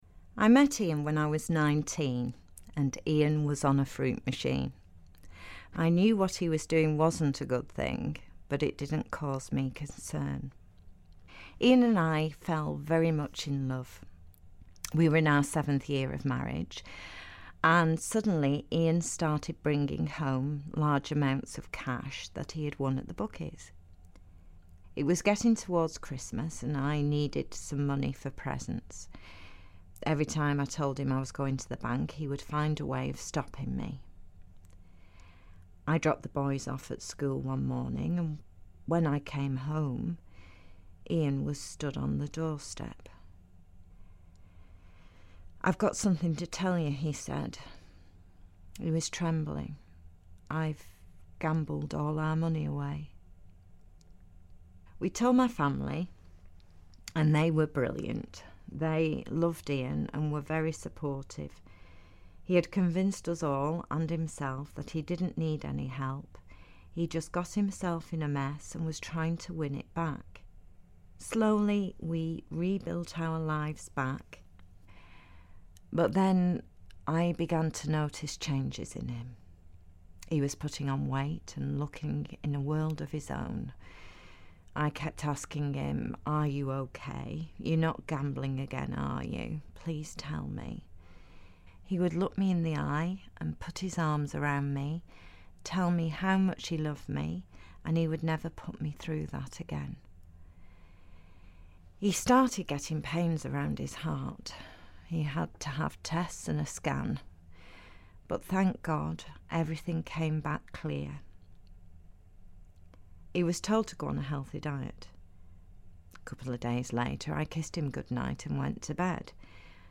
The letter is read by an actress.